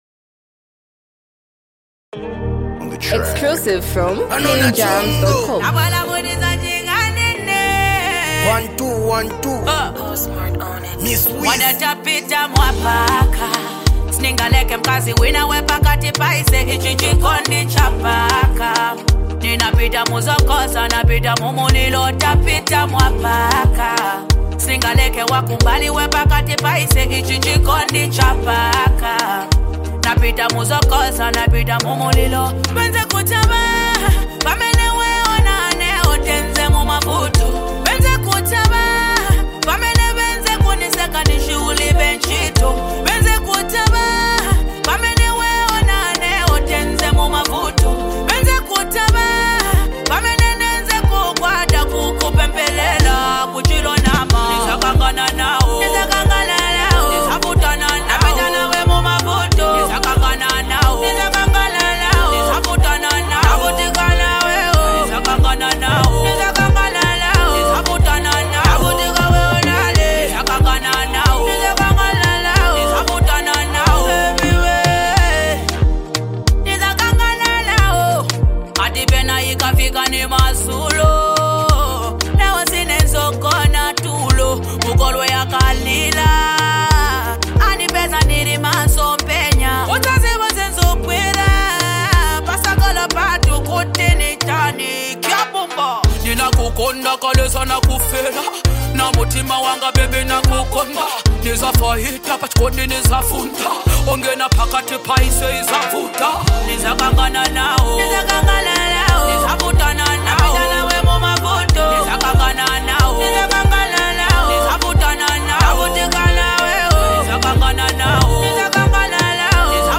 His rap adds emotional weight and realism to the storyline.